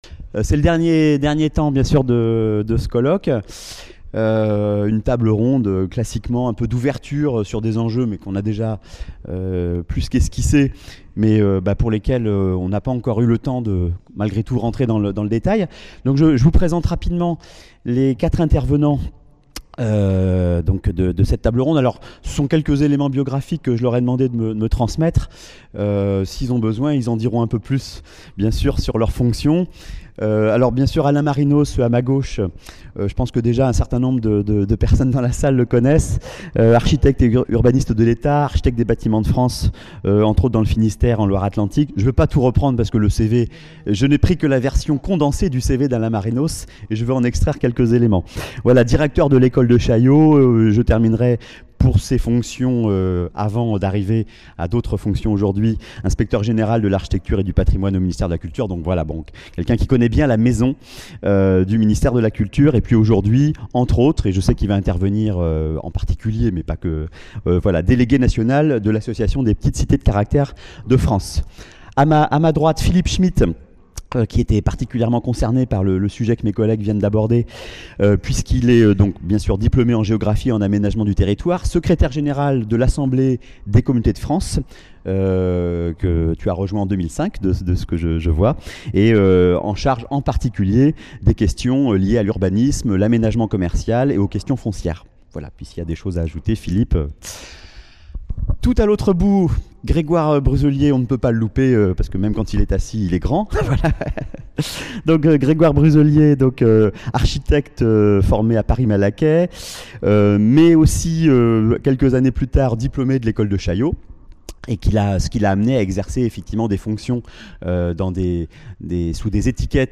Table-ronde : le PLU patrimonial à l'aube des nouveaux enjeux urbains | Canal U